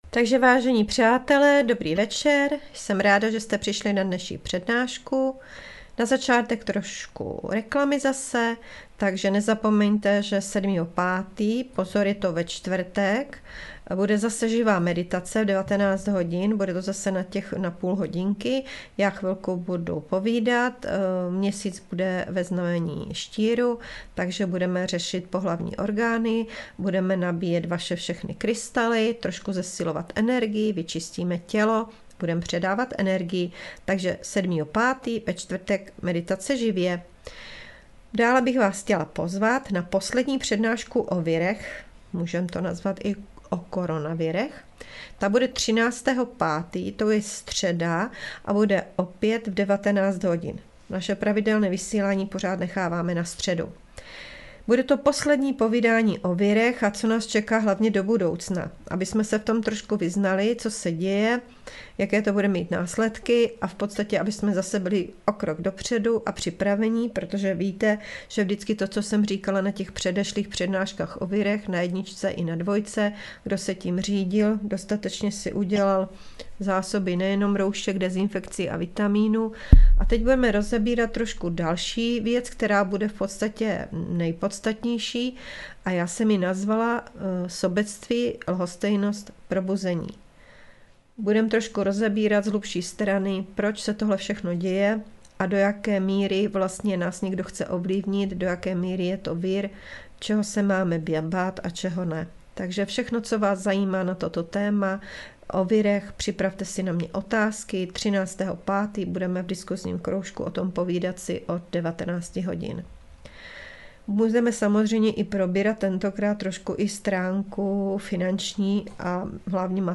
Přednáška Duše zemřelých, díl 3. - Přechod na druhou stranu